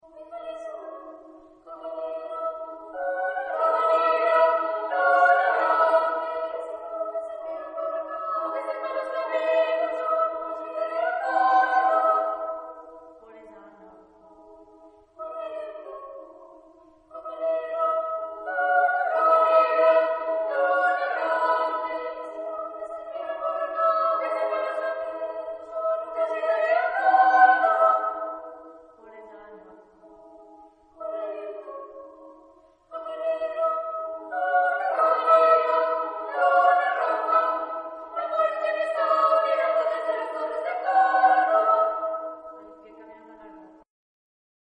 Género/Estilo/Forma: Canto coral ; Profano ; Poético
Solistas : Soprano (1) / Alto (1)  (2 solista(s) )
Tonalidad : mi (centro tonal)